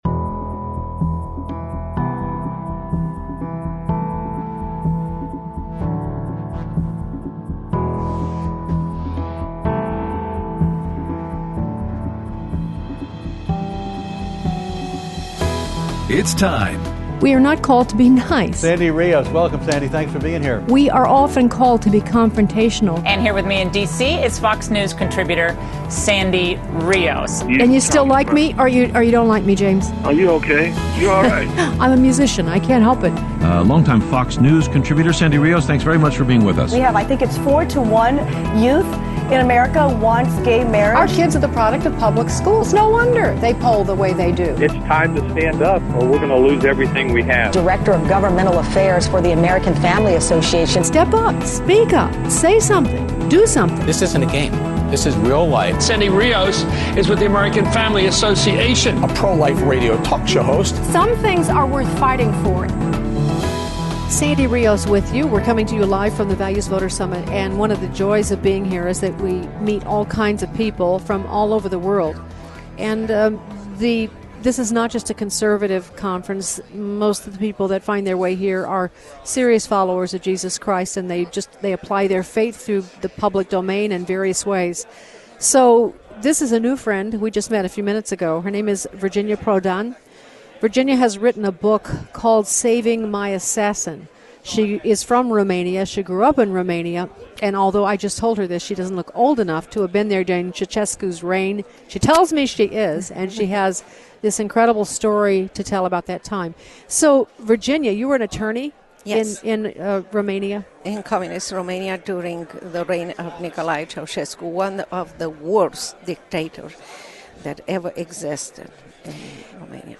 Interview
Aired Tuesday 12/27/16 on AFR 7:05AM - 8:00AM CST